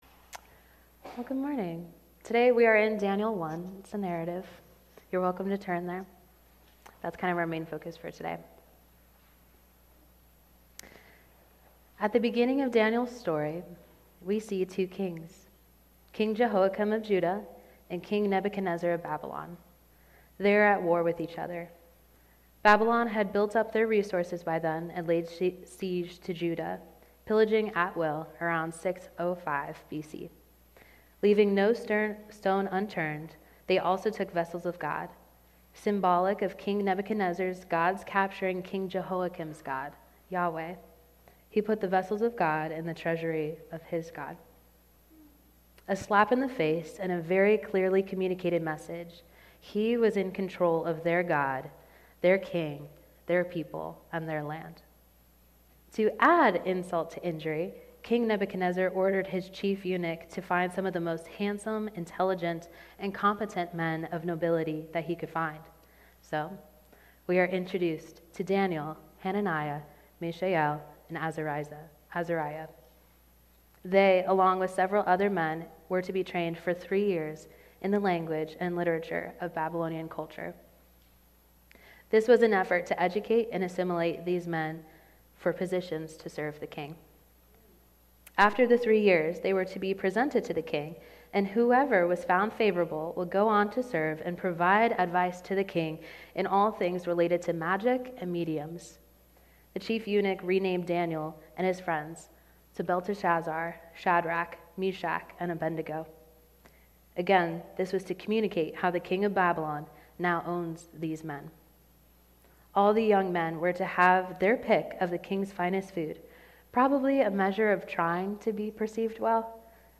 Sermon-6.2.21.mp3